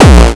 exemple de grosse basse